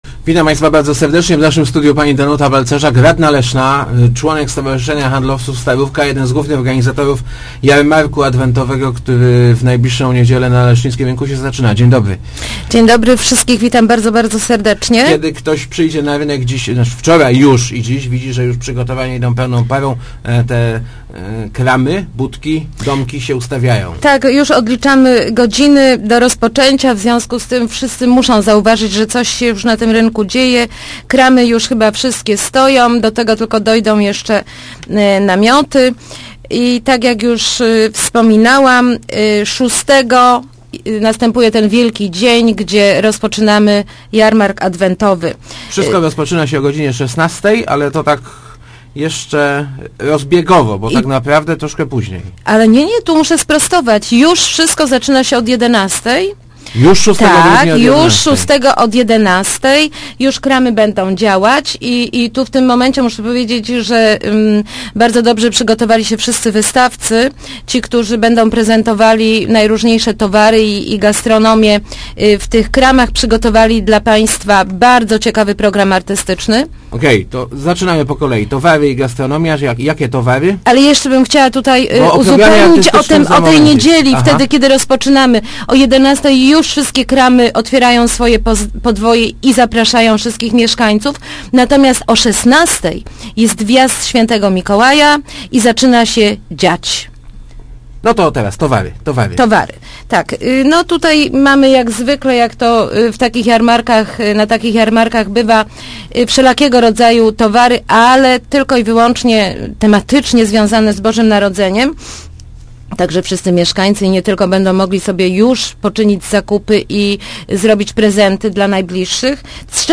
Panie, które specjalizują się w świątecznych wypiekach mogą wziąć udział w konkursie na najpiękniejszego piernika. Będzie on jedną z atrakcji tegorocznego Jarmarku Adwentowego. Jarmark zacznie się w najbliższą niedzielę – mówiła w Rozmowach Elki radna Leszna Danuta Balcerza...